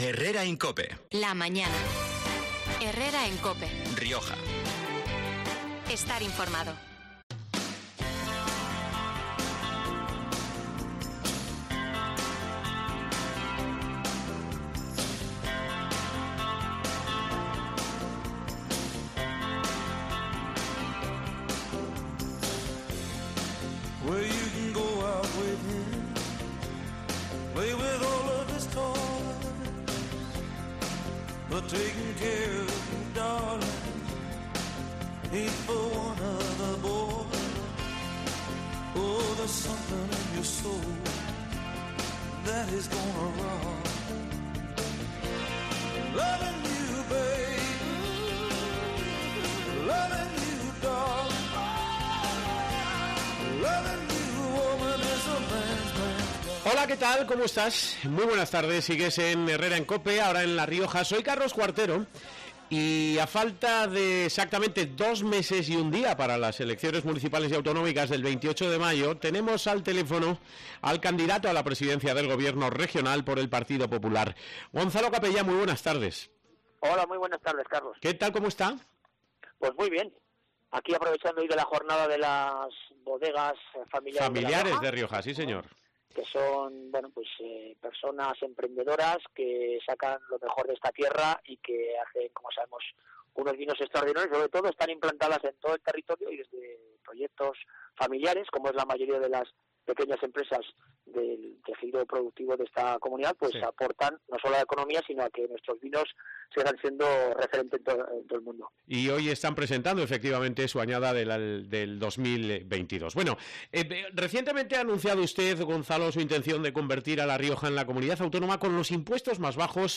Gonzalo Capellán ha intervenido en ' Herrera en COPE Rioja ' este 27 de marzo para detallar sus propuestas económicas y fiscales con el propósito de convertir la región en la Comunidad Autónoma con los impuestos más bajos de toda España .